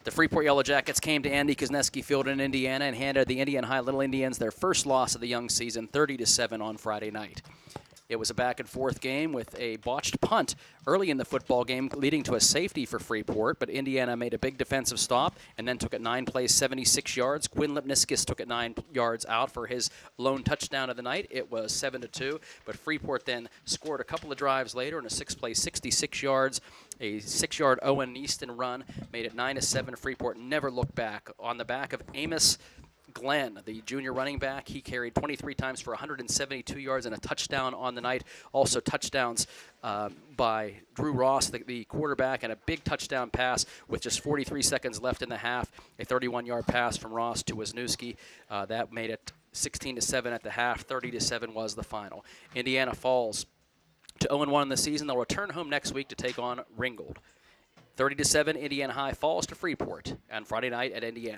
hsfb-indiana-vs-freeport-recap.wav